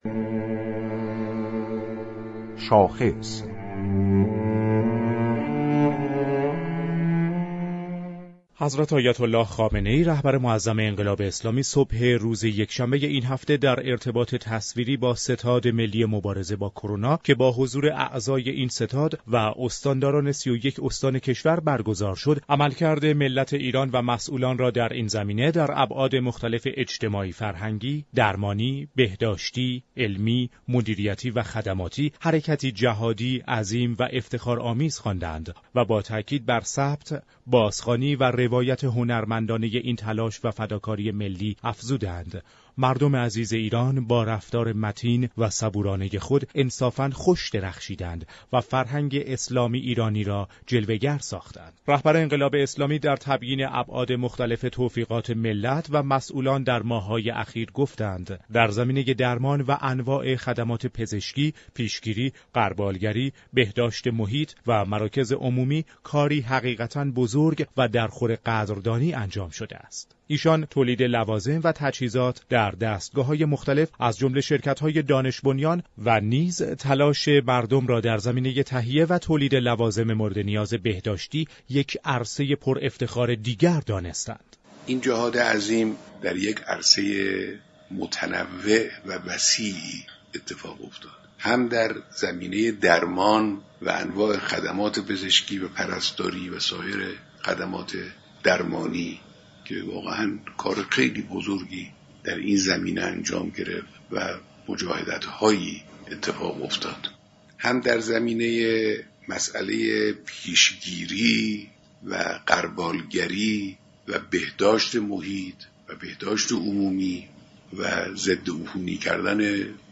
«غلامرضا شریعتی» استاندار خوزستان گفت: مقام معظم رهبری در دیدار اخیر با ستاد ملی مقابله با كرونا به ضرورت تقویت شبكه سلامت تاكید ویژه داشتند.